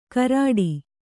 ♪ karāḍi